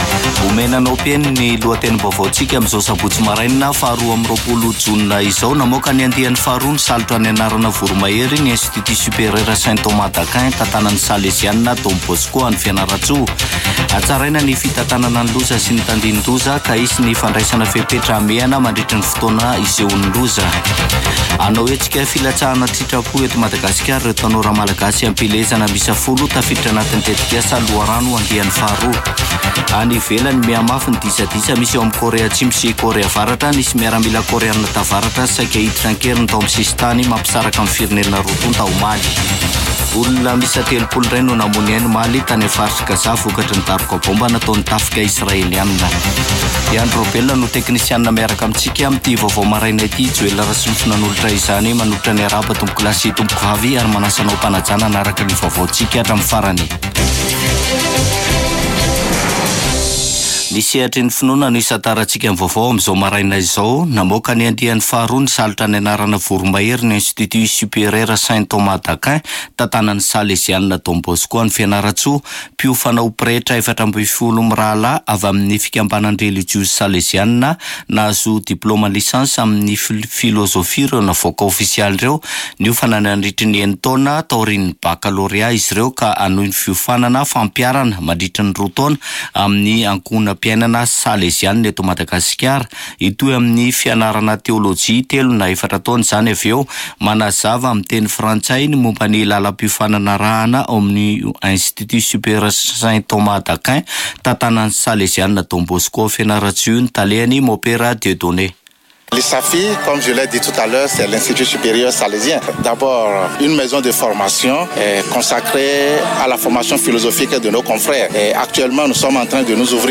[Vaovao maraina] Sabotsy 22 jona 2024